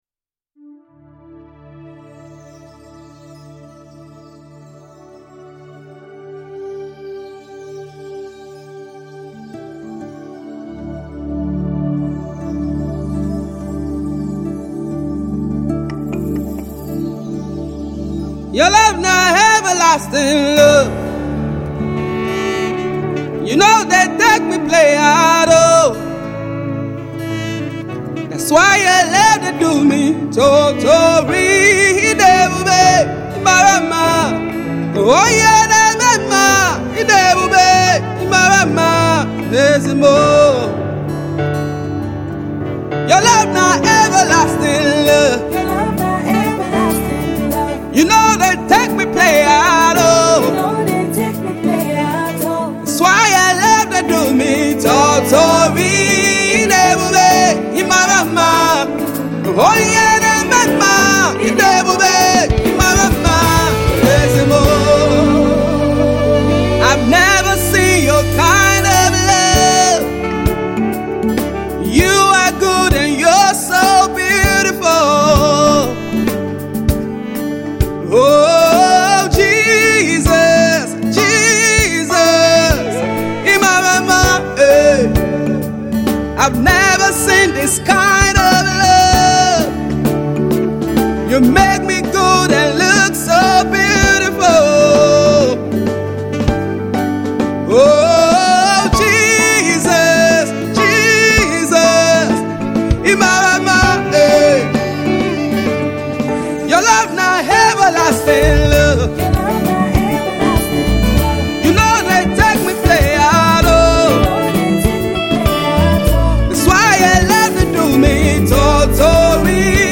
Gospel Singer-Songwriter